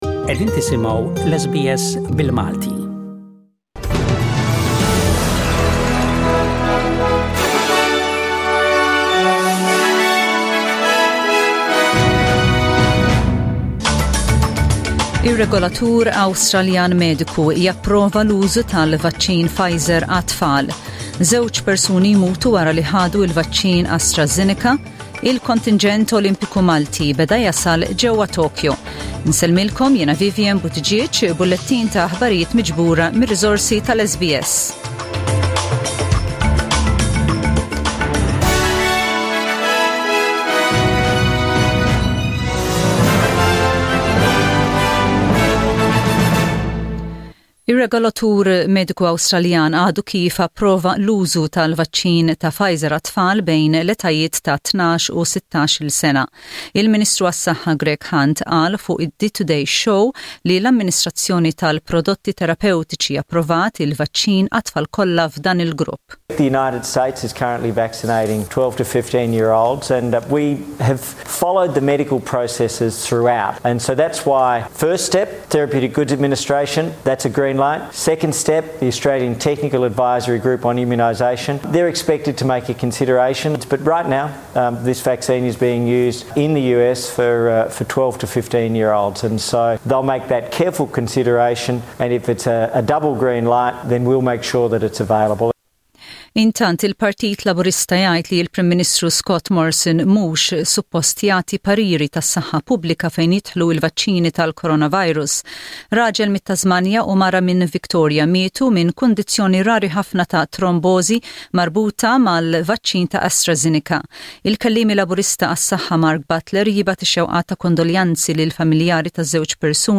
SBS Radio | Aħbarijiet bil-Malti: 23/07/21